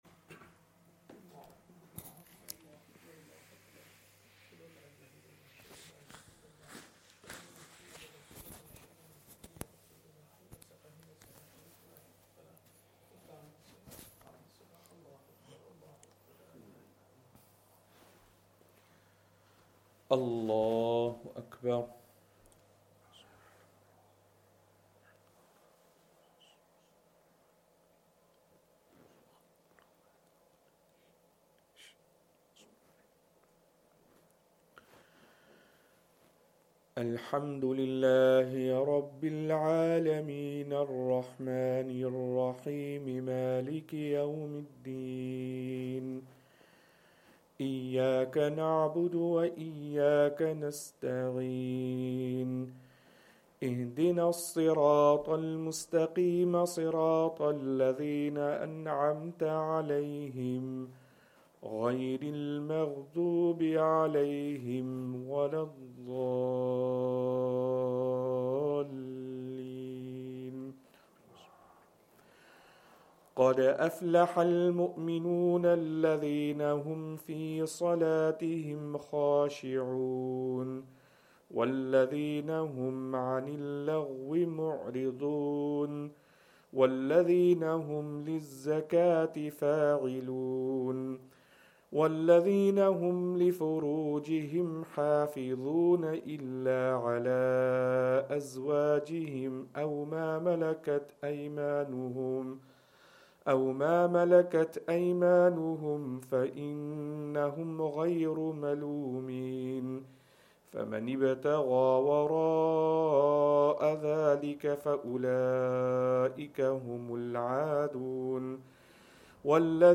Fajr
Madni Masjid, Langside Road, Glasgow